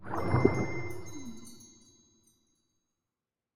Minecraft Version Minecraft Version latest Latest Release | Latest Snapshot latest / assets / minecraft / sounds / entity / glow_squid / ambient1.ogg Compare With Compare With Latest Release | Latest Snapshot